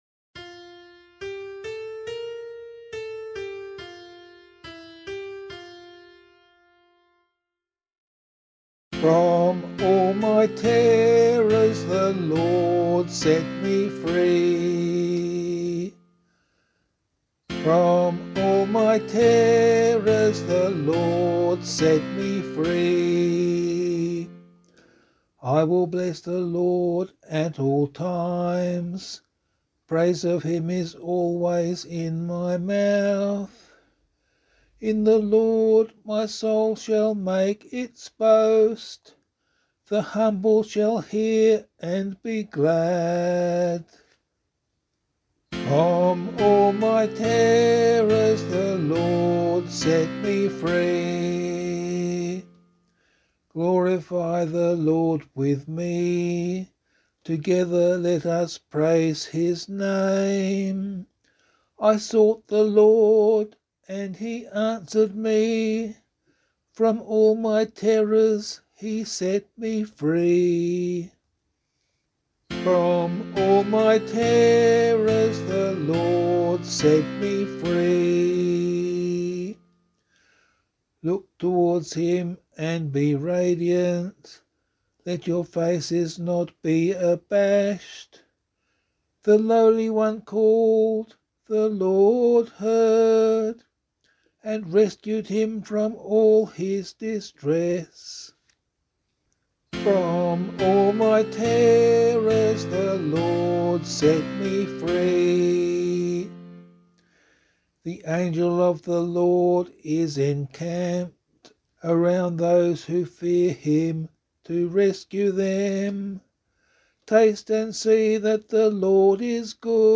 280 Peter and Paul Day Psalm [APC - LiturgyShare + Meinrad 6] - vocal.mp3